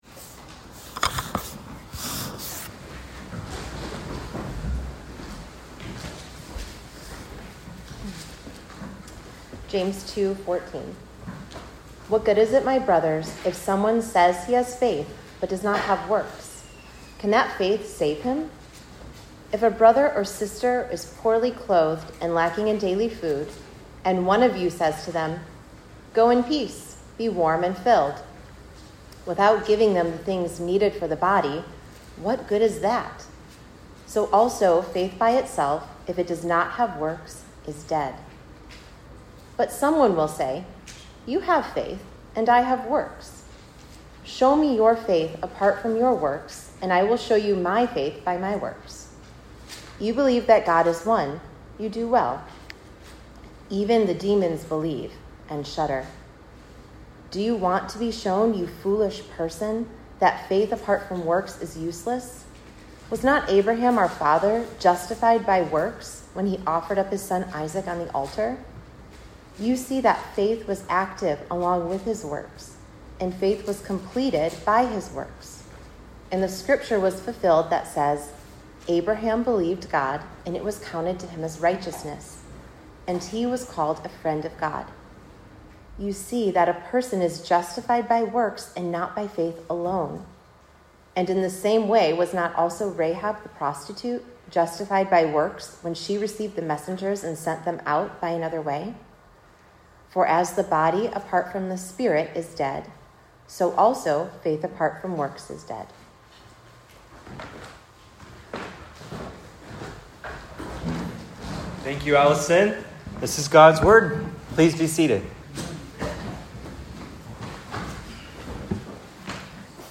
James 2:14-26 “Faith without Works” Sermon
(Preached at Cross of Christ Fellowship in Naperville on 7.13.25)